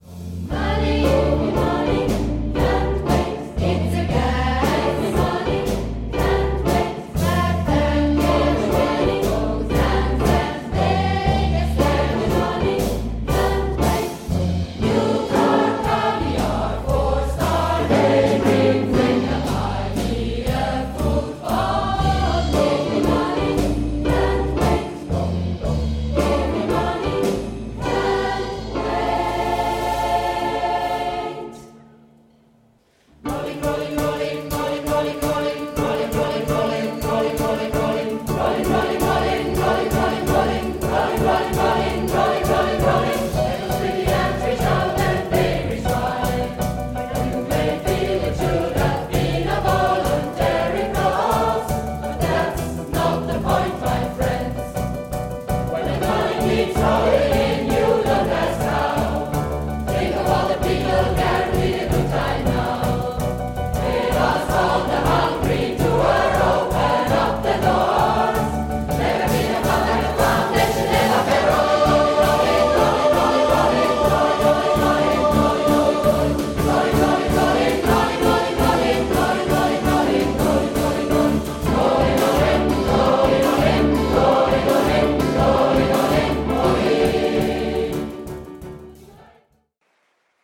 Speziell: 7/8-Takt; inkl.